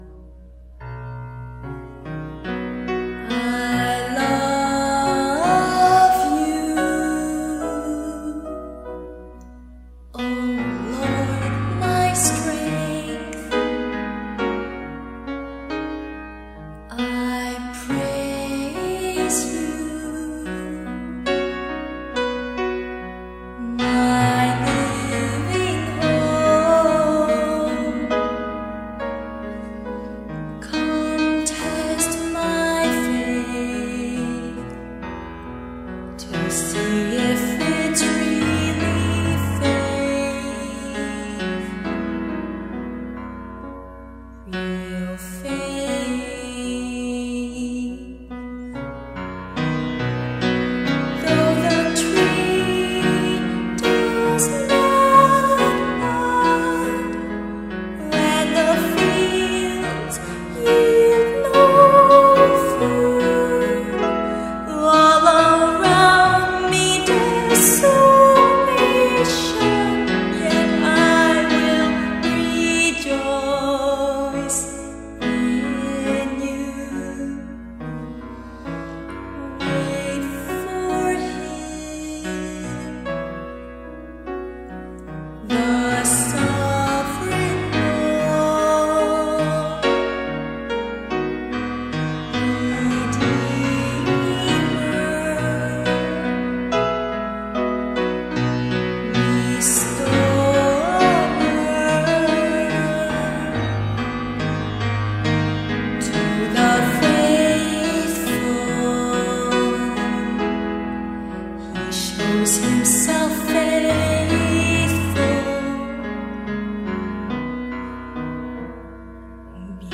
I can’t help wince at my voice but if the Scriptures sung in crude,
Lovely song – very peaceful.
This was replete with errors and pitch that fell short but I couldn’t pull even this off now.
It was he who did the recording and drew out my voice.